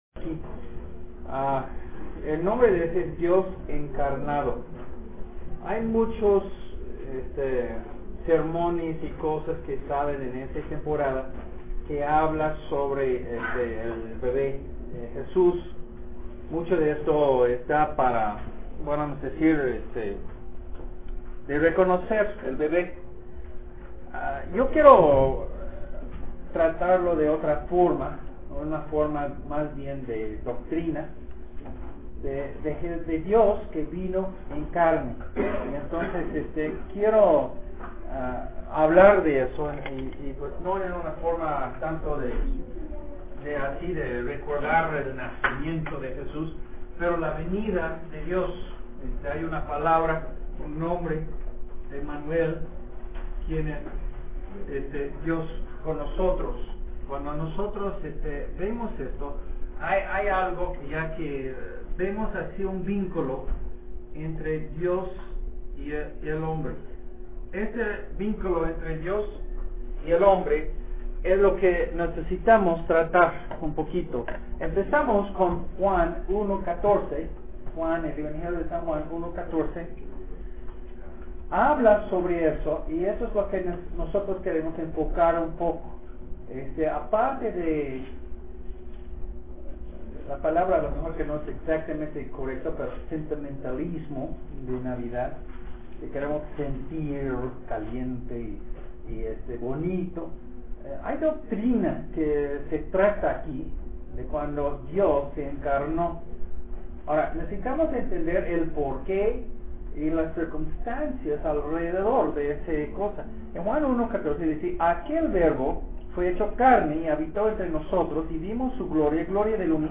un sermón del pulpito